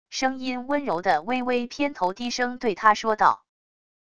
声音温柔的微微偏头低声对她说道wav音频